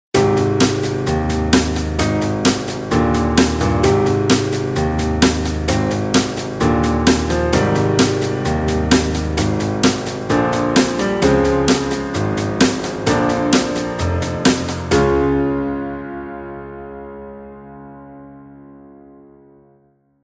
描述：Pop|Happy
标签： Piano Drums
声道立体声